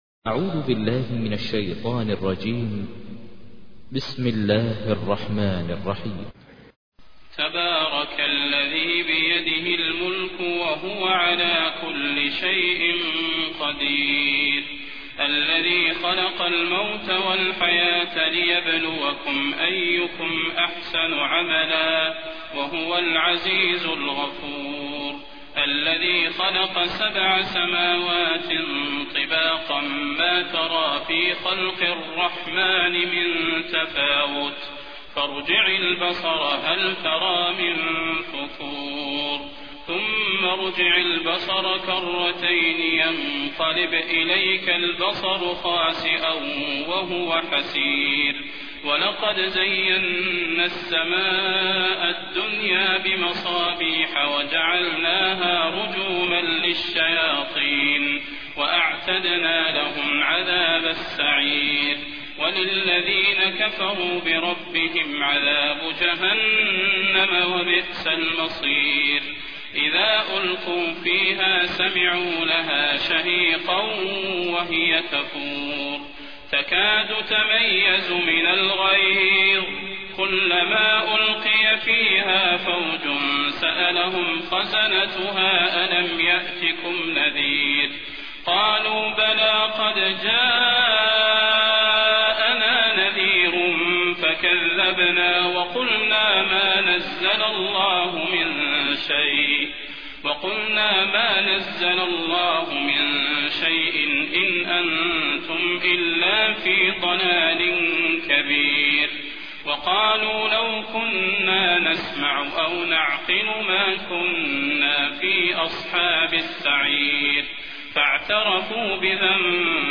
تحميل : 67. سورة الملك / القارئ ماهر المعيقلي / القرآن الكريم / موقع يا حسين